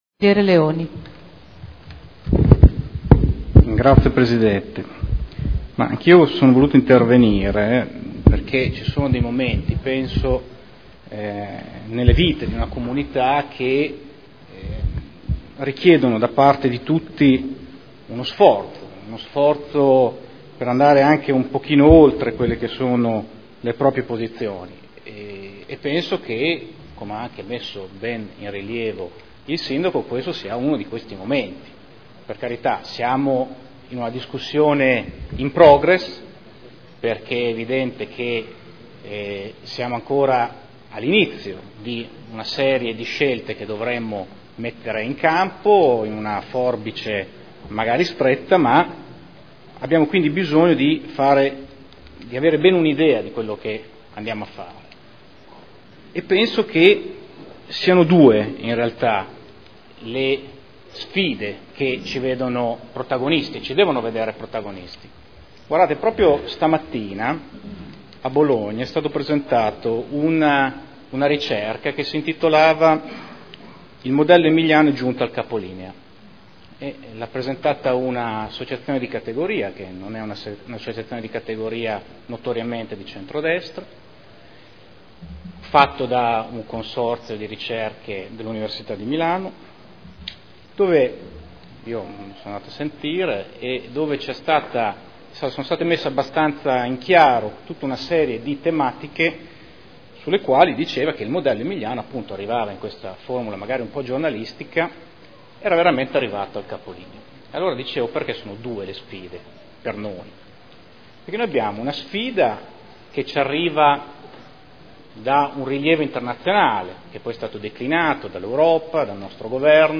Seduta del 26 settembre 2011 Ricadute della manovra del Governo sul Bilancio del Comune di Modena – Dibattito